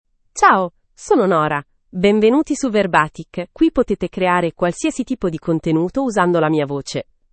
Nora — Female Italian (Italy) AI Voice | TTS, Voice Cloning & Video | Verbatik AI
Nora is a female AI voice for Italian (Italy).
Voice sample
Listen to Nora's female Italian voice.
Female
Nora delivers clear pronunciation with authentic Italy Italian intonation, making your content sound professionally produced.